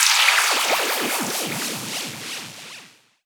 VEC3 Scratching FX